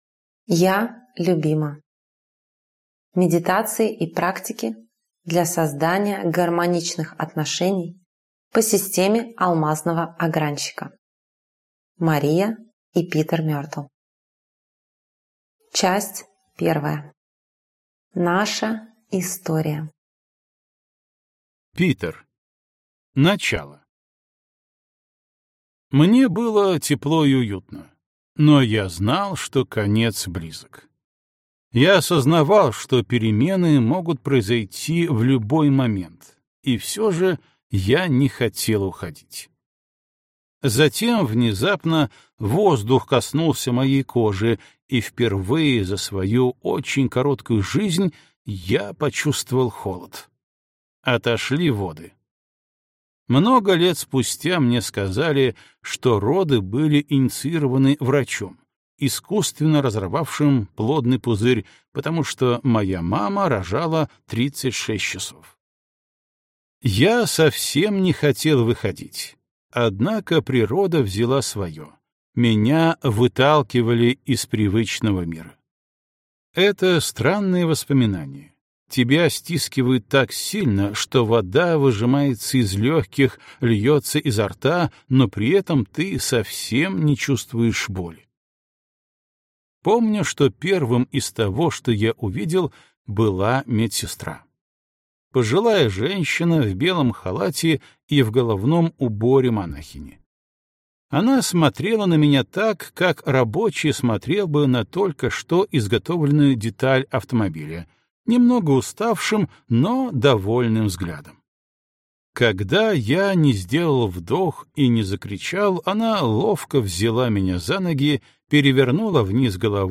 Аудиокнига Я любима. Система Алмазный Огранщик: медитации и практики для создания гармоничных отношений | Библиотека аудиокниг